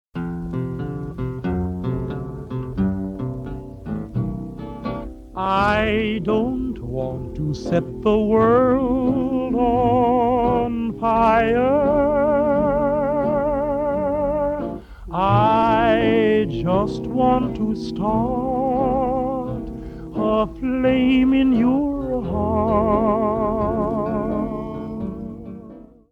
ретро